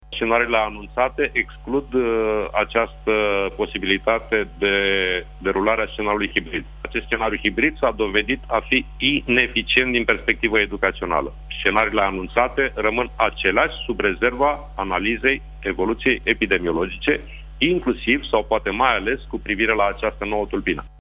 El a declarat, la Digi 24, că programul remedial va începe imediat ce se vor întoarce copiii fizic la școală, din februarie până în mai, vreme de 16 spătămâni.
Sorin Câmpeanu exclude, pe de altă parte, un scenariu hibrid din 8 februarie: